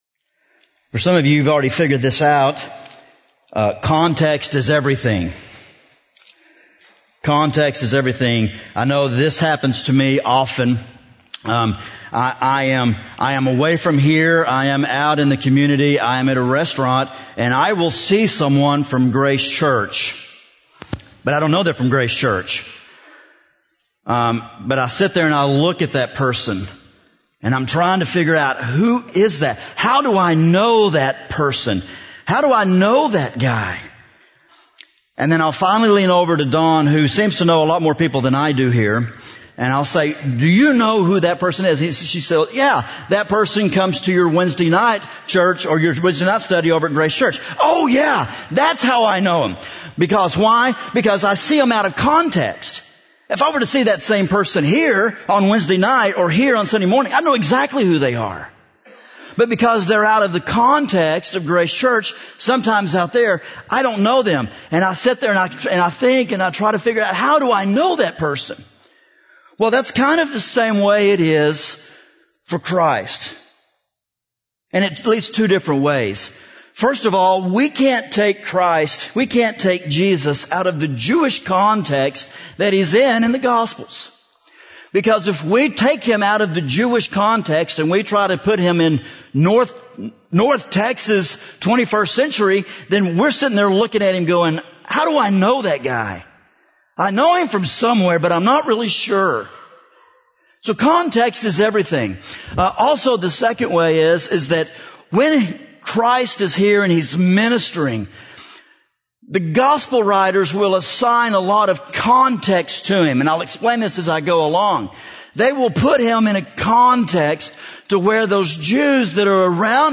A message from the series “Glimpses of Glory.”